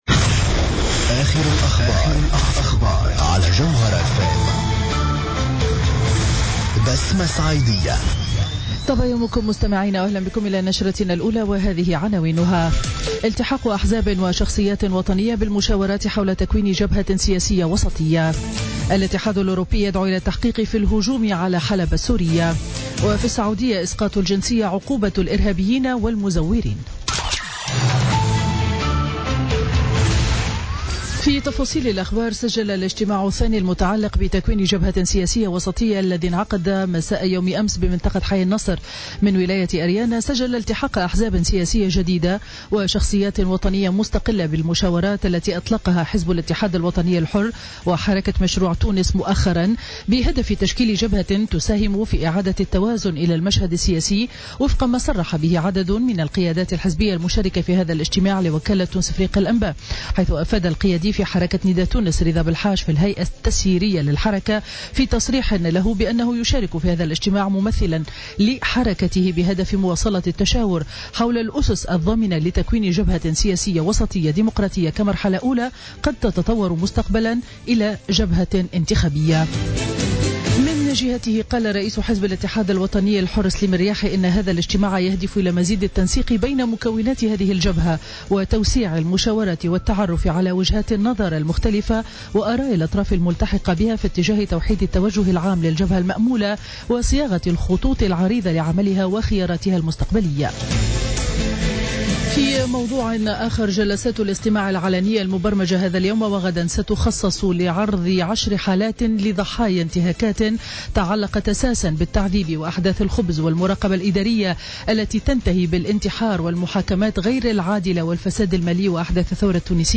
نشرة أخبار السابعة صباحا ليوم الجمعة 16 ديسمبر 2016